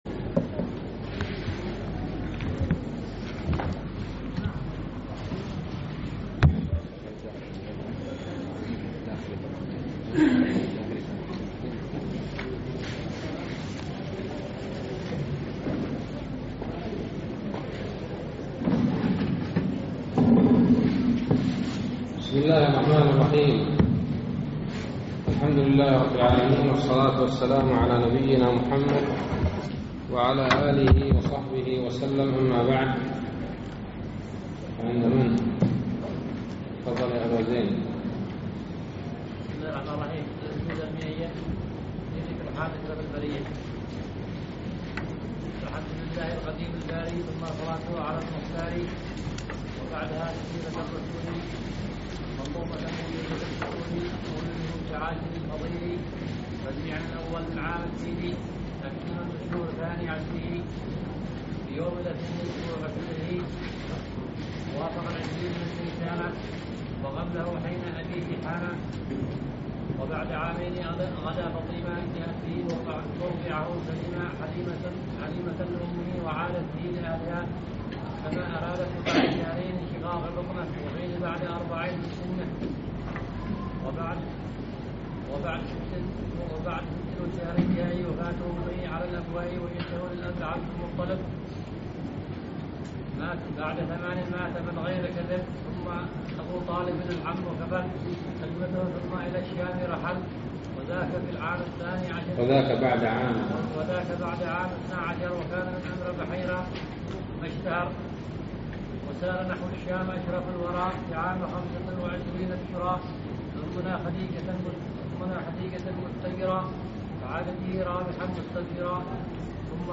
الدرس التاسع عشر من شرح كتاب التوحيد